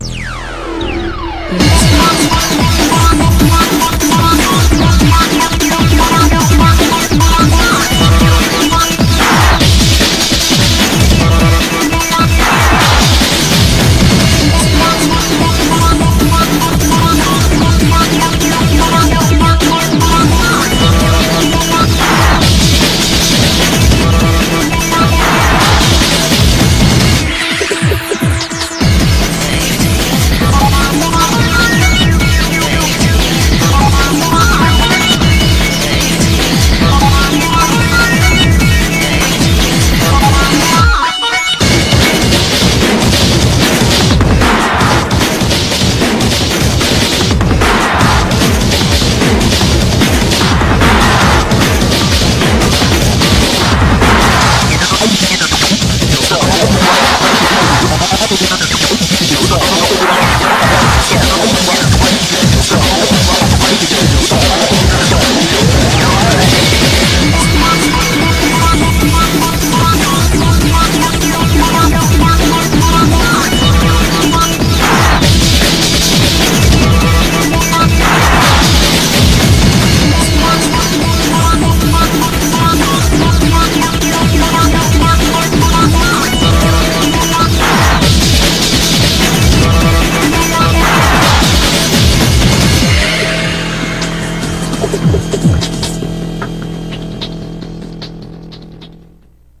BPM150
MP3 QualityMusic Cut